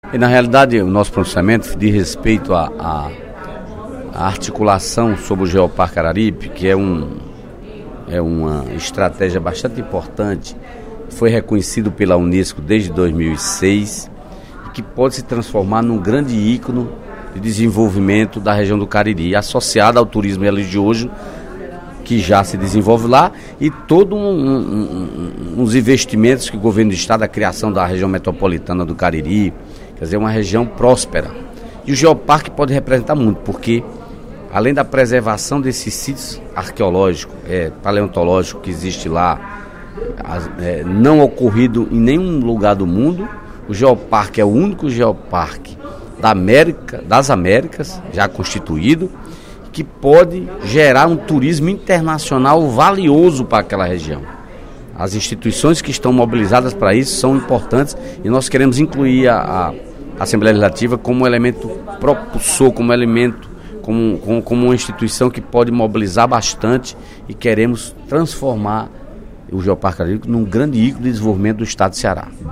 Em pronunciamento no primeiro expediente da sessão plenária desta sexta-feira (08/02), o deputado Dedé Teixeira (PT) destacou a importância da biodiversidade e da riqueza paleontológica do Geoparque do Araripe, que se estende por seis municípios cearenses (Barbalha, Crato, Juazeiro do Norte, Missão Velha, Nova Olinda e Santana do Cariri), em um território de 3.796 km².